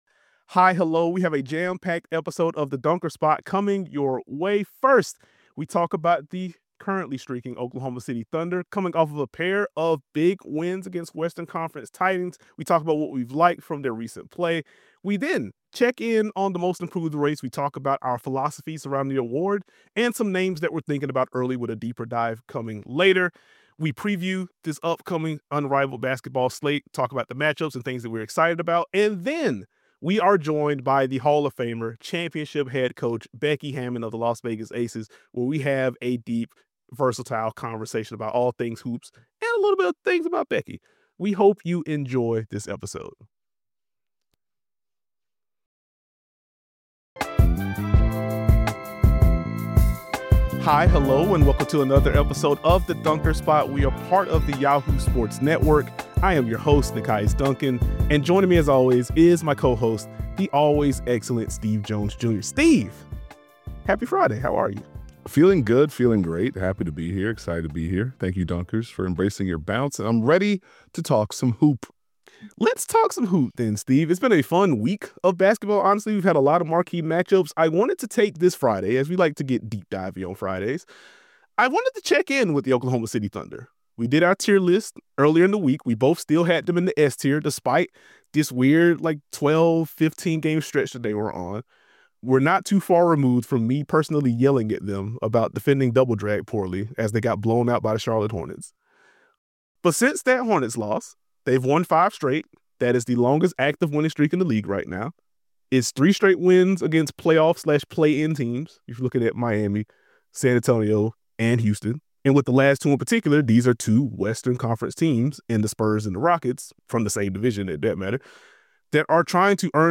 Finally, Hall of Famer Becky Hammon (head coach, Las Vegas Aces) joins the show to discuss her journey and upcoming documentary, her evolution as a coach, coaching A'ja Wilson and so much more.
50:46 -- Becky Hammon interview